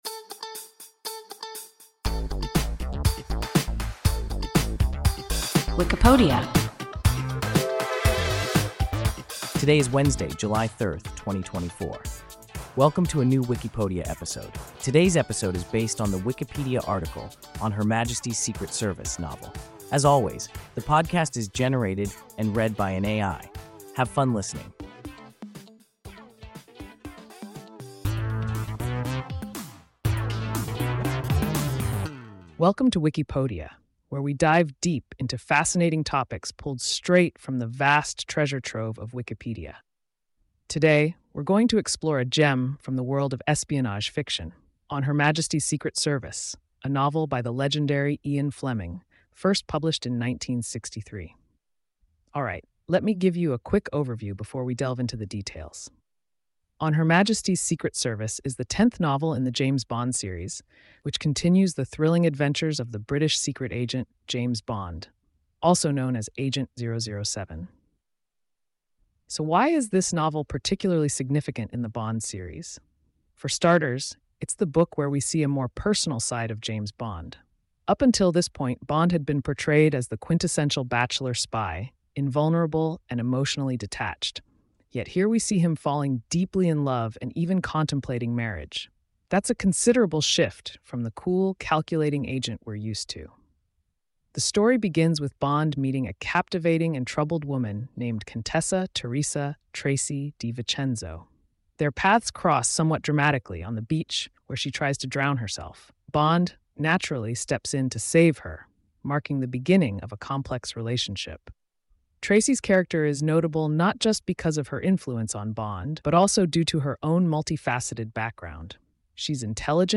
On Her Majesty’s Secret Service (novel) – WIKIPODIA – ein KI Podcast